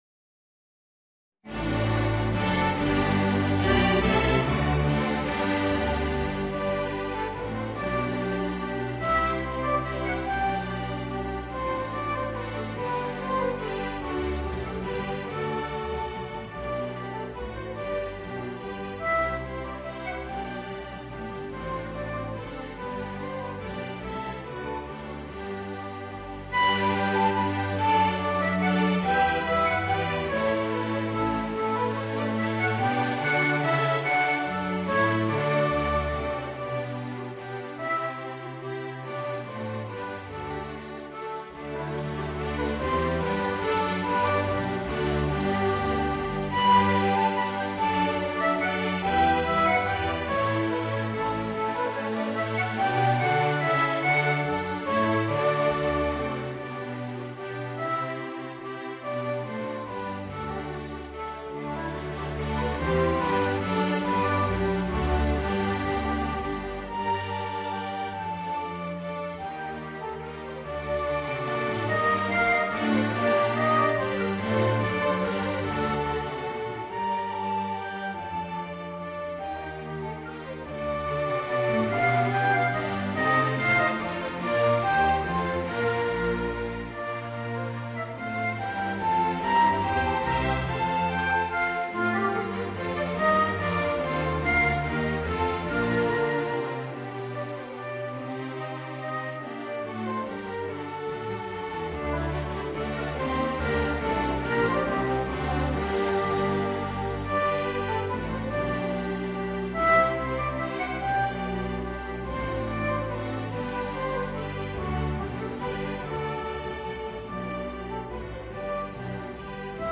Voicing: Violin and String Quintet